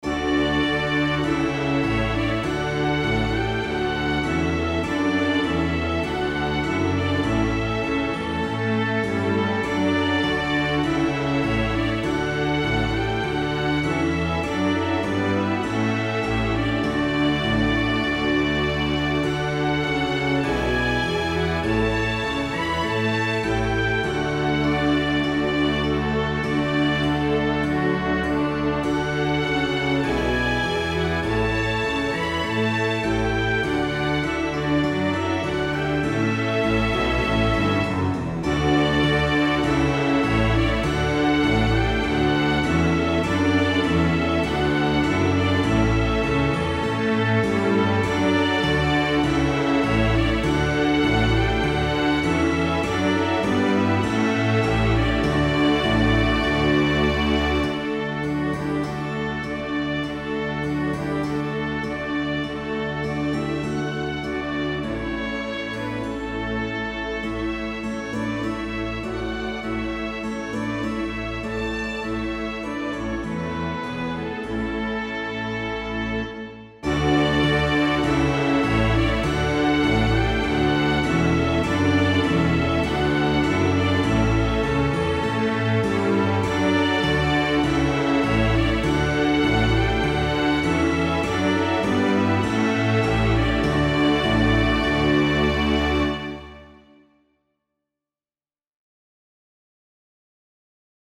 I did another experimental version of the piece, and I added a French Horn and replaced the Solo Trumpet with a different flavor of Solo Trumpet, so in this version there is a French Horn and a Solo Trumpet, with the French Horn to the mid-right and the Solo Trumpet more to the top-center, where the Solo Trumpet is playing the same notes as the French Horn but an octave higher, which also is the case with Violins I at far-left . . .
I also used a different flavor of Harpsichord in an attempt to control the presence of the Harpsichord, which appears to be impossible with the Miroslav Philharmonik flavors, since the Harpsichord tends to be either (a) entirely absent or (b) obviously present, and my general thinking is that it need to be present, although I would prefer it to be present in a smoother way, but controlling the Harpsichord in a very detailed way requires switching to Digital Performer 7, so I settled on it being always present, since I did not want to switch to mixing in Digital Performer 7 . . .